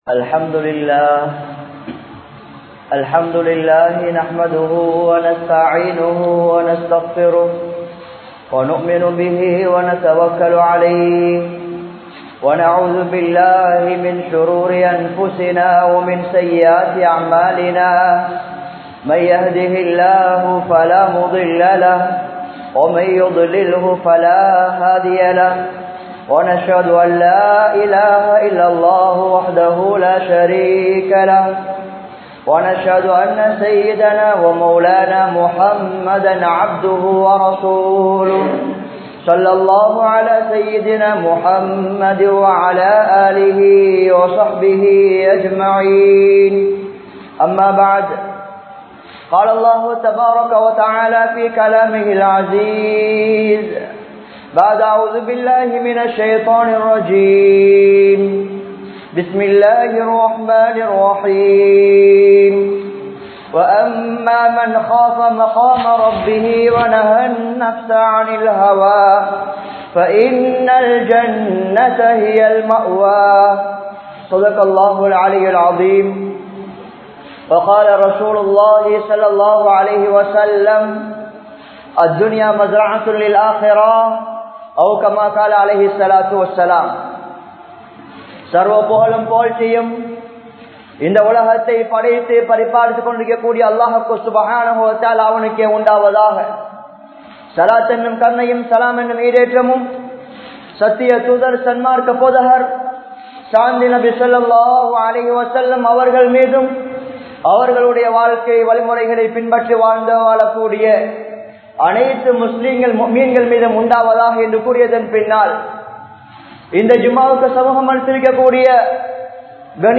Uiroattamulla Amalhal (உயிரோட்டமுள்ள அமல்கள்) | Audio Bayans | All Ceylon Muslim Youth Community | Addalaichenai
Ambalanduwa Jumua Masjidh